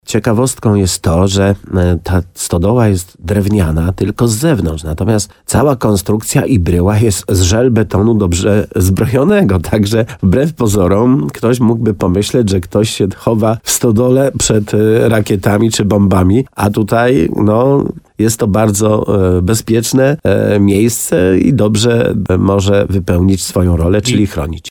Jak przyznał wójt Leszek Skowron w programie Słowo za Słowo w radiu RDN Nowy Sącz, pozory często mylą, dlatego mimo pierwszego wrażenia, wybrane miejsce będzie dobrze pełniło swoją funkcję.